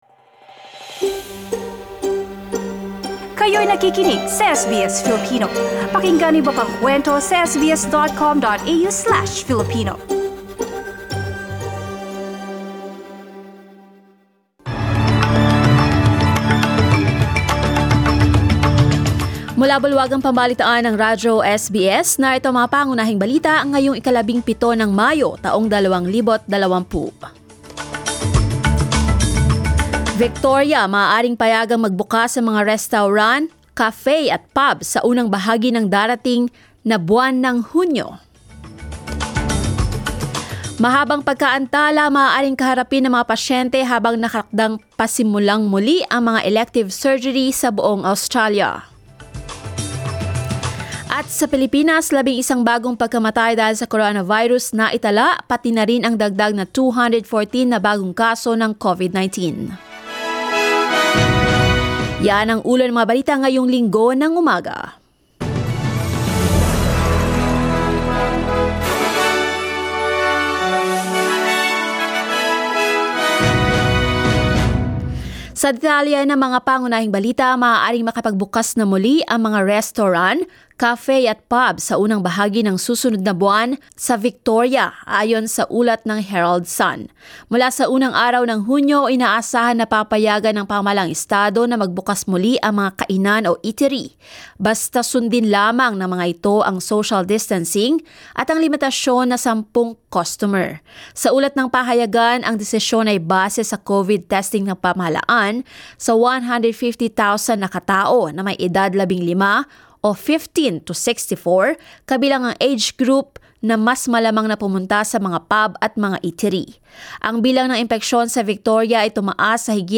SBS News in Filipino, Sunday 17 May